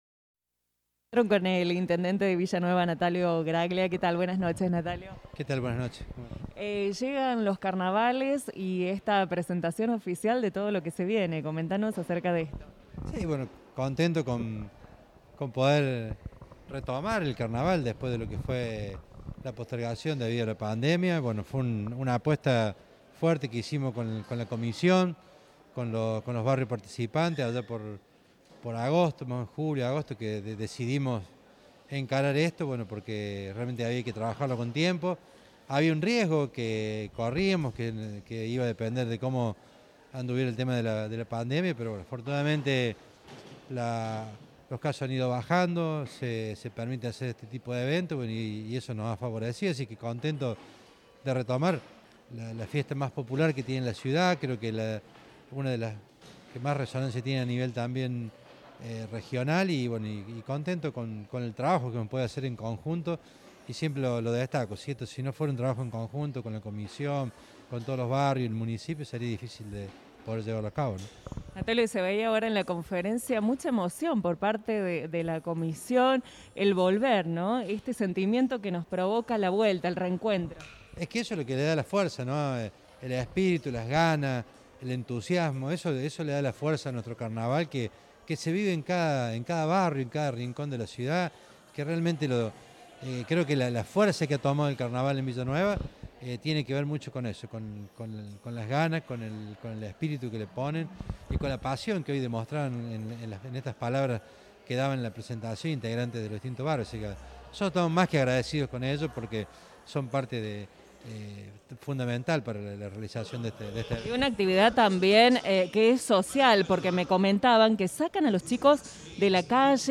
Esta fue la palabra de Natalio Graglia para Radio Centro en la presentación de los Carnavales: